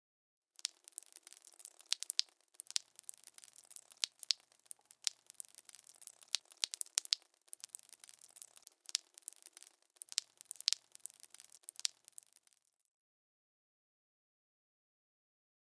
Blood Dripping with Thick Splattering Sound
horror